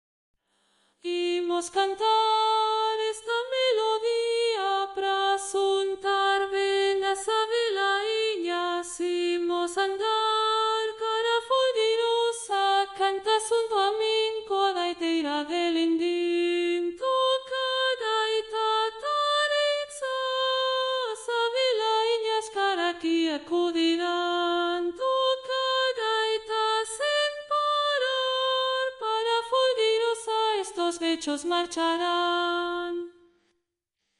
soprano.mp3